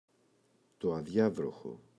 αδιάβροχο, το [a’ðʝavroxo]